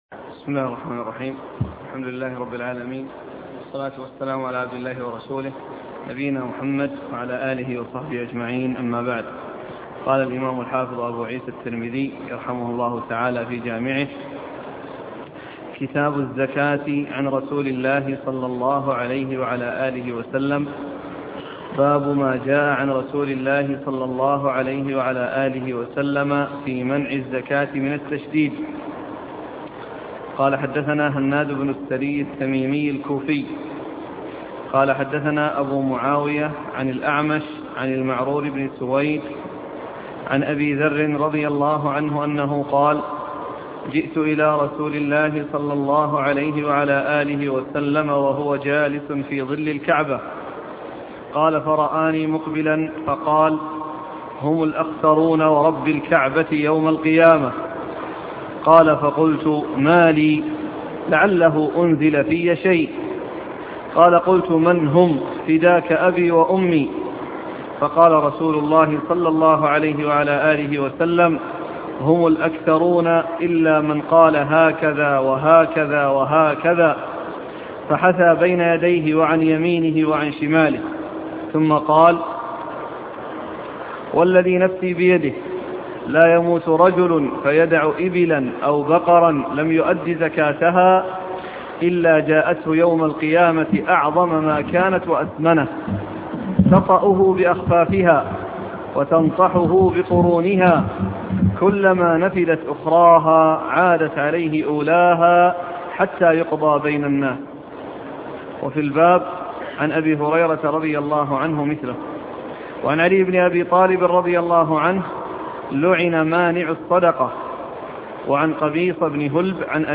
شرح سنن الترمذي الدرس الخامس والثمانون